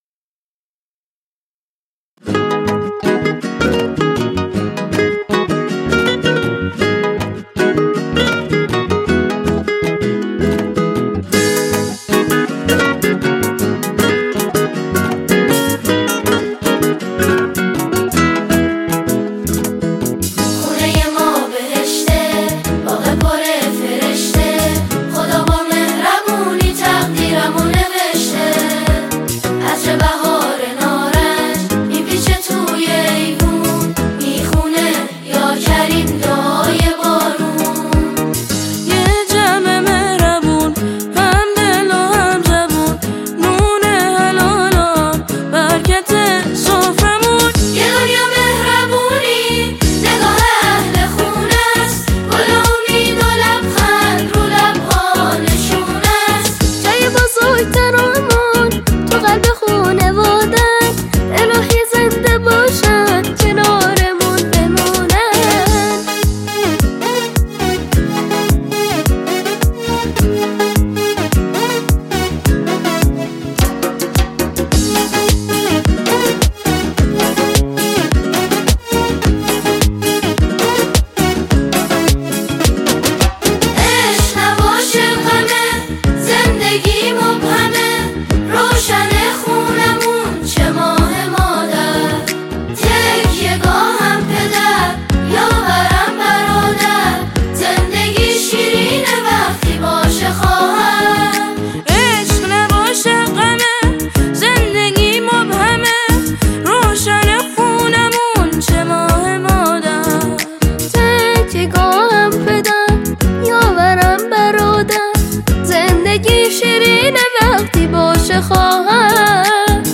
اثری لطیف، خانوادگی و سرشار از عاطفه است